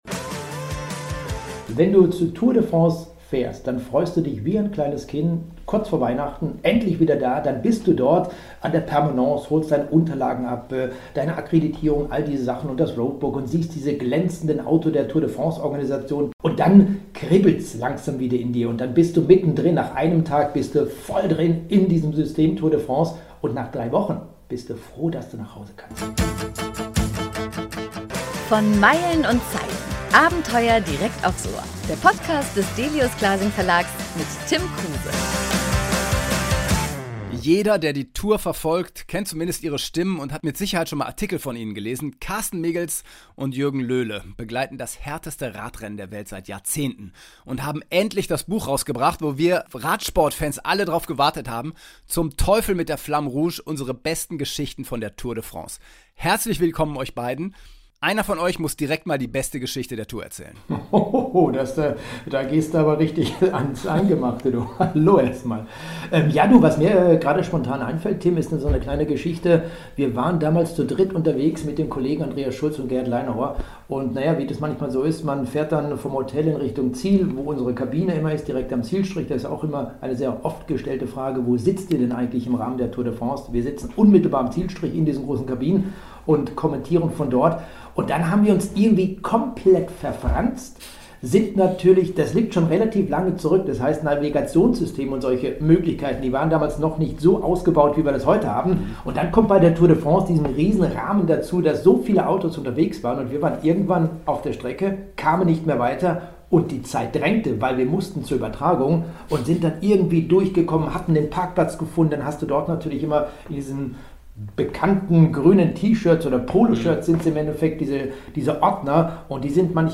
Im heutigen Gespräch